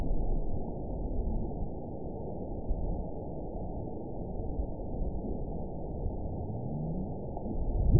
event 918042 date 04/27/23 time 19:37:52 GMT (2 years, 1 month ago) score 9.14 location TSS-AB05 detected by nrw target species NRW annotations +NRW Spectrogram: Frequency (kHz) vs. Time (s) audio not available .wav